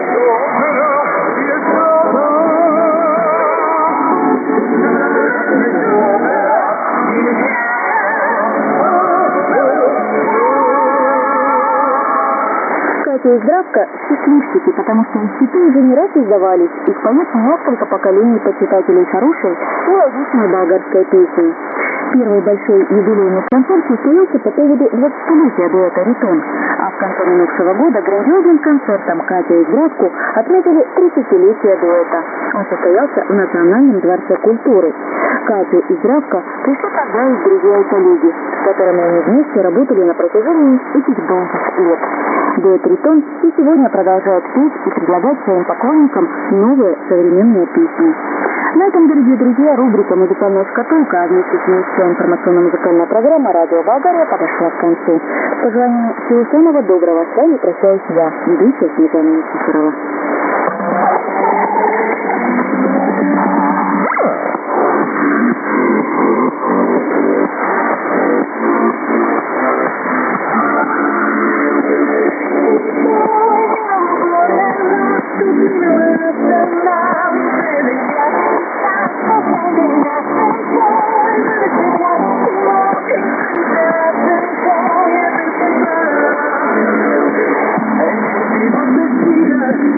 IS: interval signal
ID: identification announcement